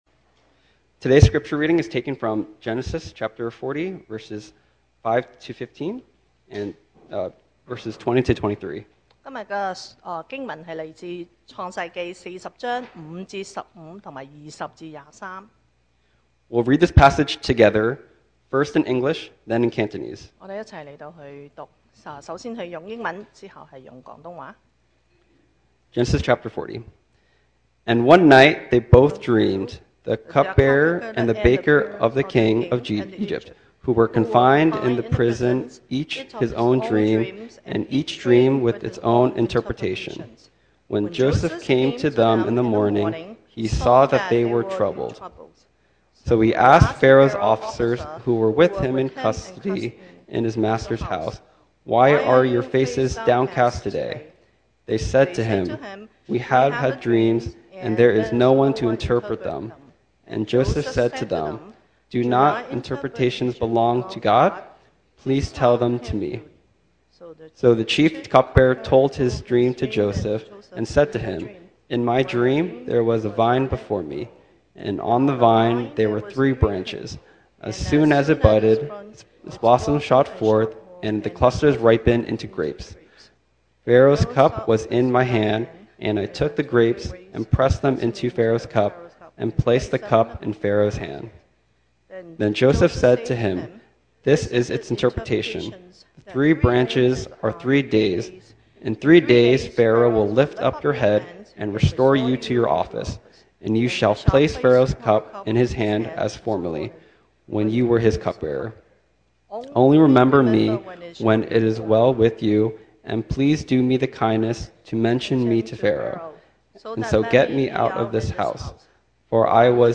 2025 sermon audios
Service Type: Sunday Morning